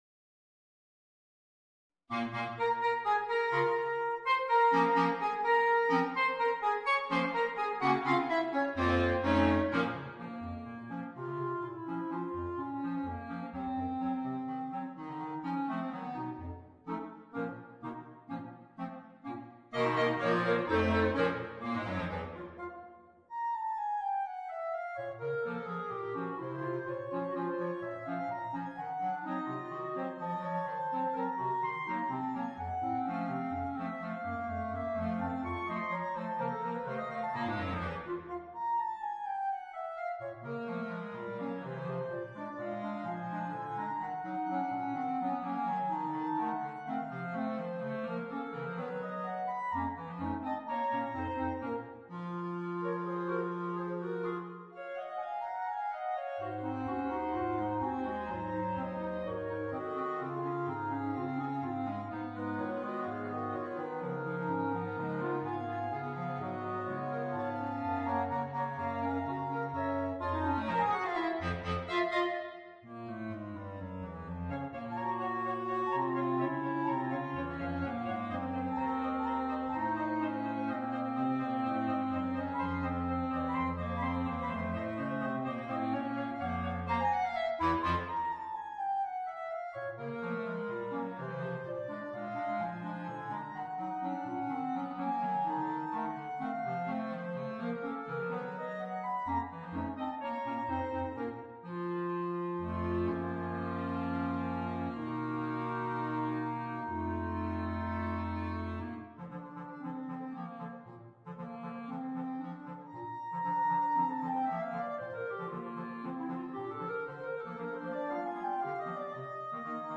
4 clarinetti e clarinetto basso